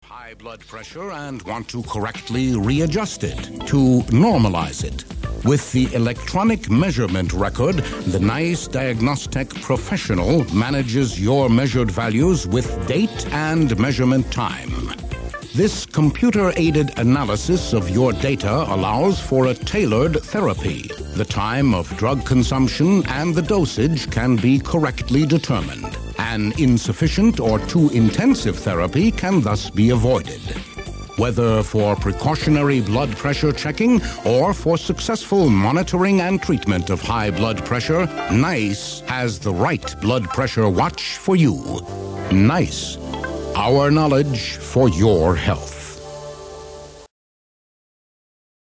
Amerikanischer Sprecher (transatlantisch) mit verschiedenen Stimmlagen und Akzente, TV Moderator, Schauspieler, Werbesprecher, Film-Vertonungen, Übersetzungen
mid-atlantic
Sprechprobe: eLearning (Muttersprache):